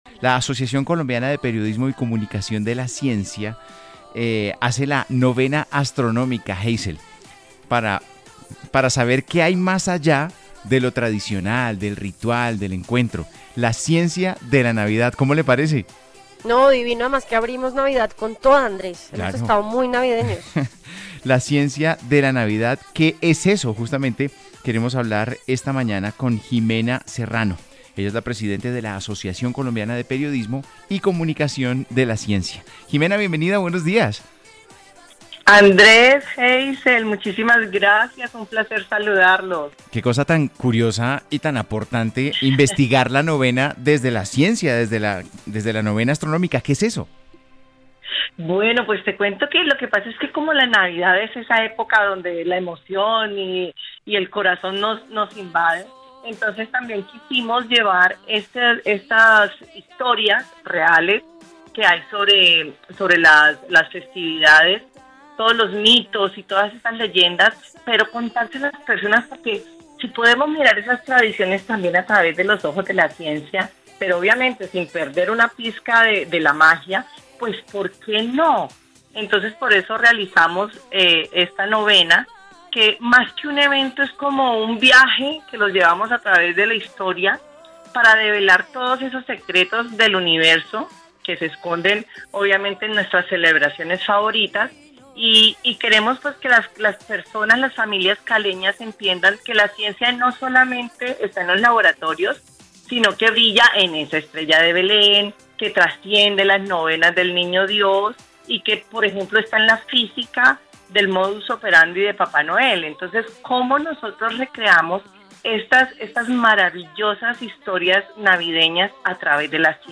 Radio en vivo